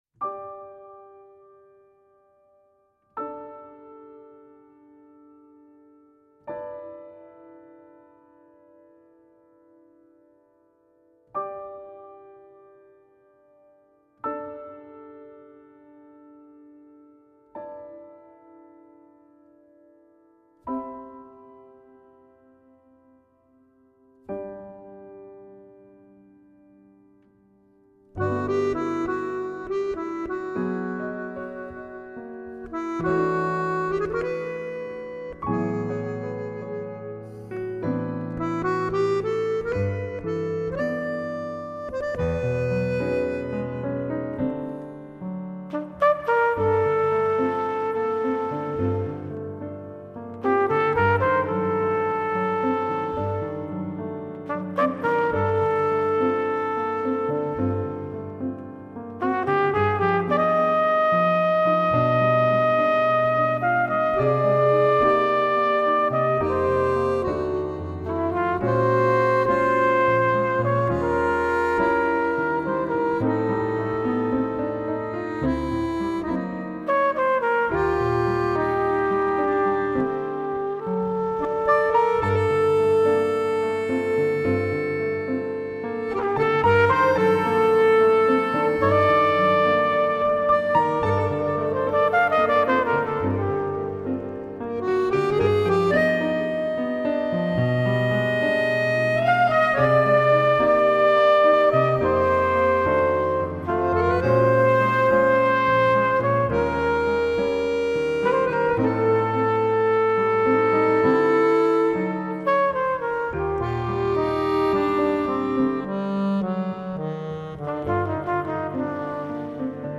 Abbiamo raggiunto telefonicamente Richard Galliano per ripercorrere insieme a lui la storia e l’evoluzione artistica di questa virtuosa formazione.